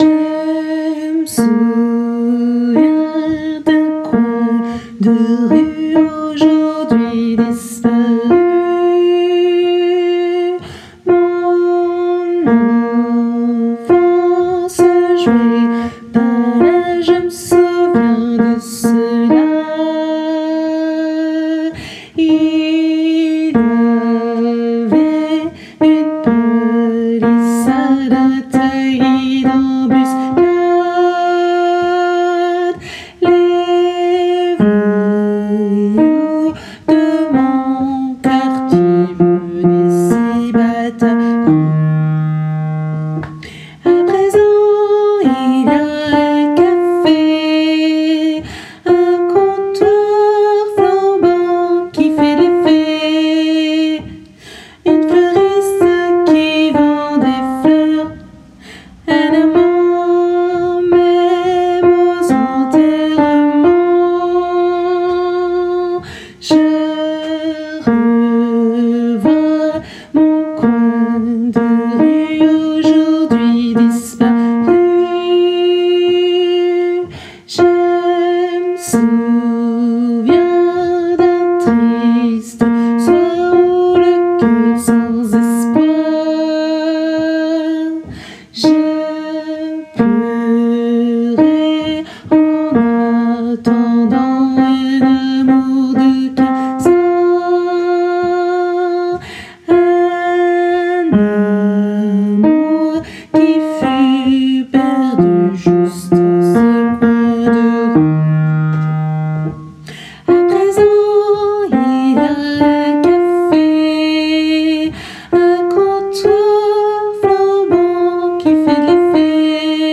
Mp3 versions chantées
Tenor